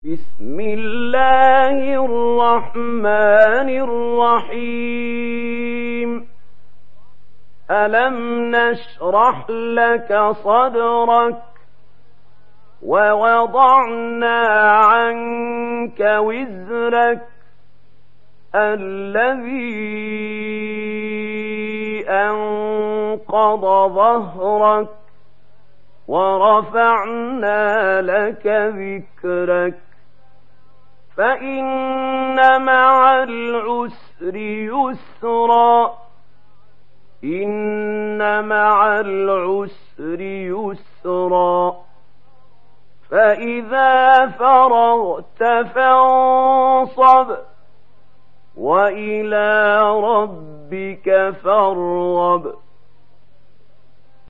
Surah Ash Sharh mp3 Download Mahmoud Khalil Al Hussary (Riwayat Warsh)